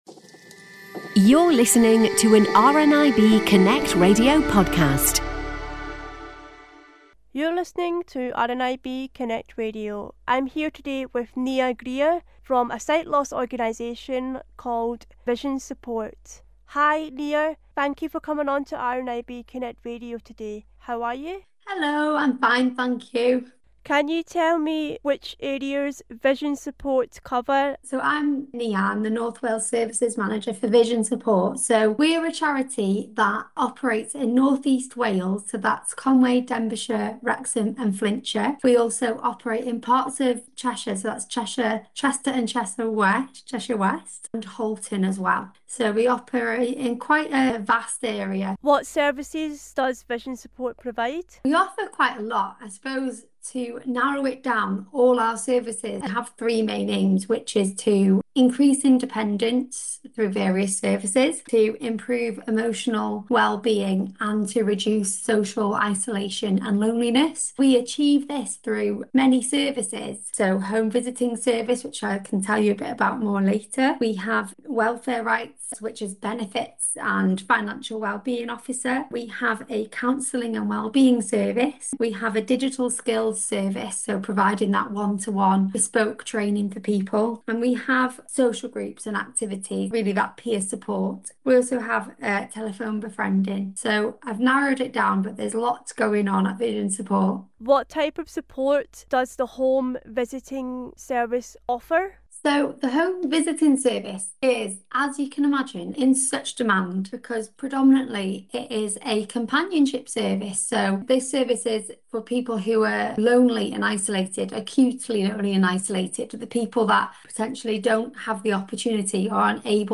Vision Support, Interview